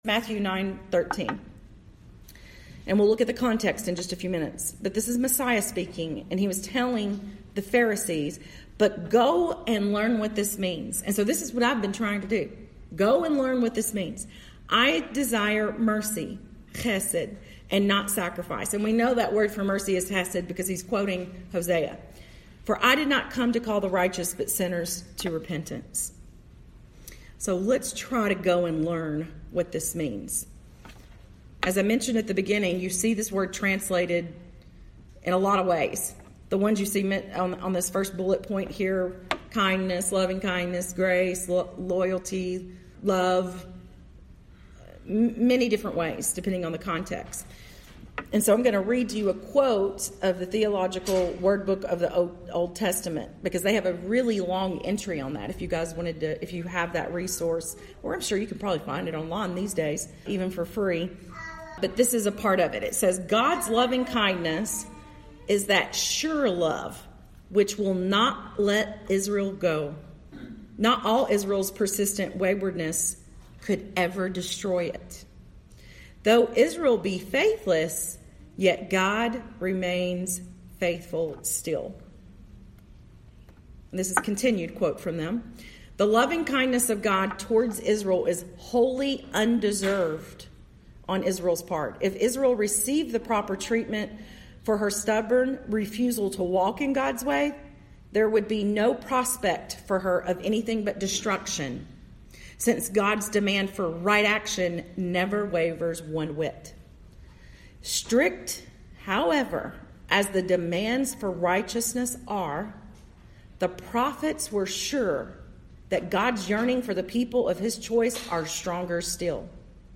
This recording is a breakout session delivered on July 2,2020 at Revive called Extraordinary Chesed.